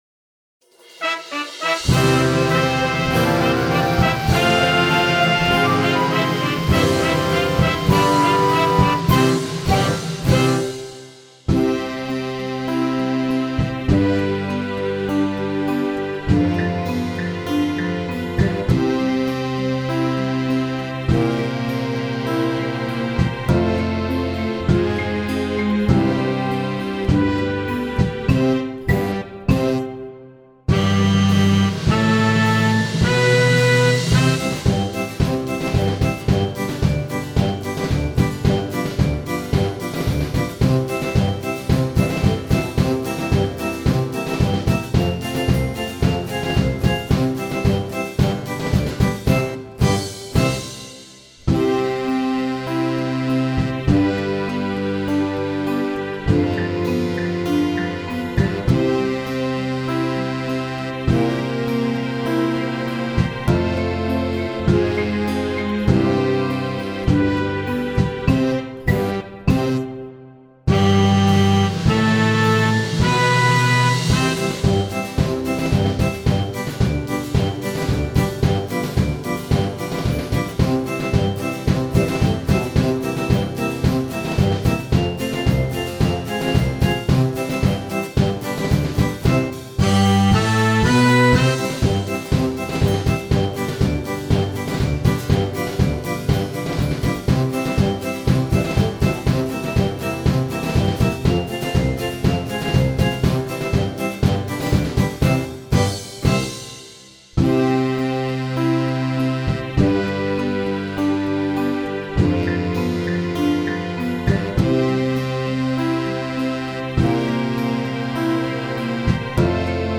Base musicale